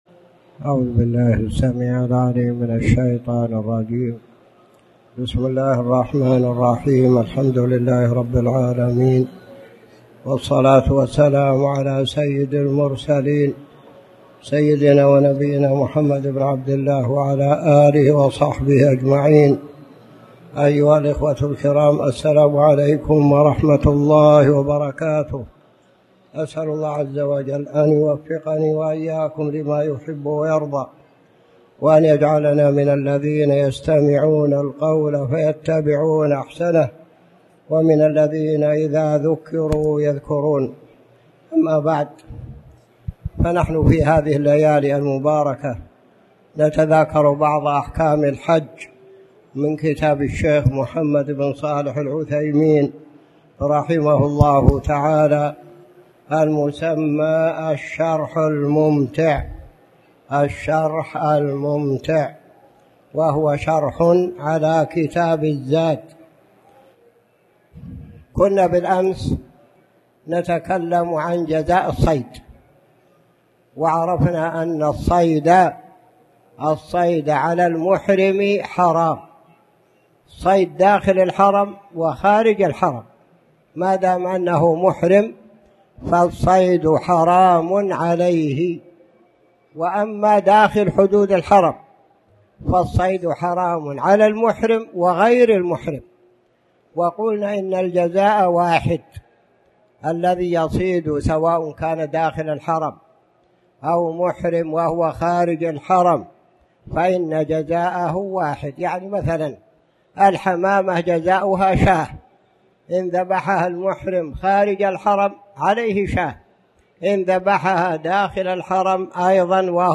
تاريخ النشر ٢١ ذو الحجة ١٤٣٨ هـ المكان: المسجد الحرام الشيخ